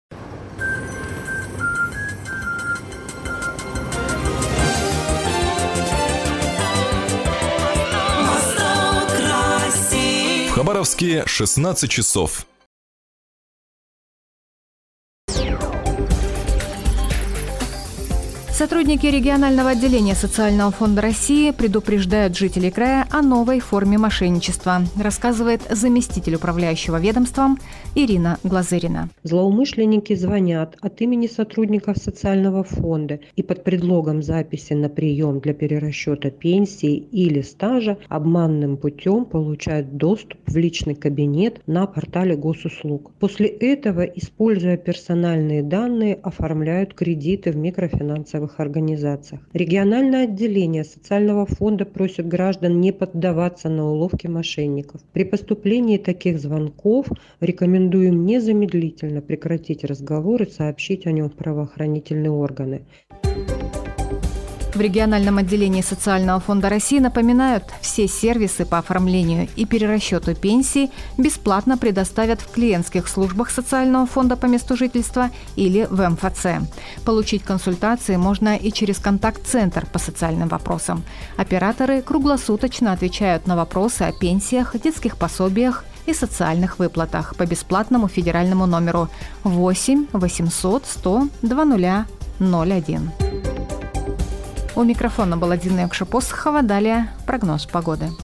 Как защититься от мошенников Об этом - репортаж радиостанции "Восток России"